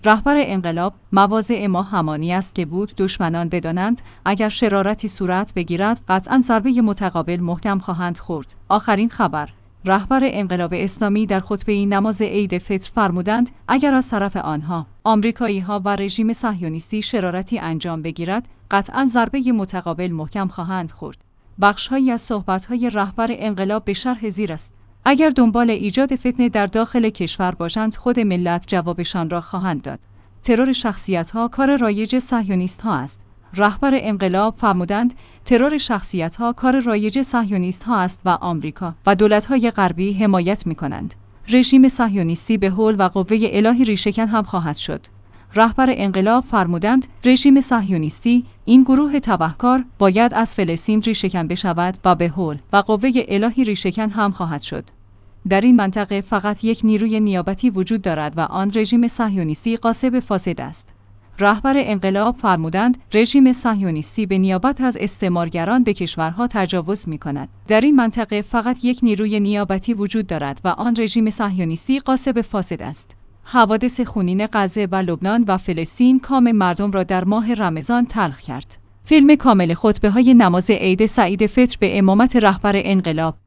آخرین خبر/ رهبر انقلاب اسلامی در خطبه ای نماز عید فطر فرمودند: اگر از طرف آنها، آمریکایی ها و رژیم صهیونیستی شرارتی انجام بگیرد، قطعاً ضربه متقابل محکم خواهند خورد.